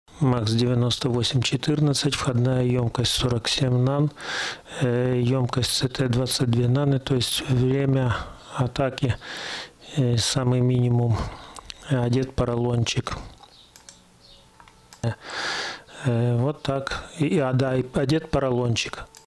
Сигнал ОС плохо стал фильтроваться, пульсации после выпрямления модулируют полезный сигнал,
а в итоге искажения, да еще и асимметрия появилась.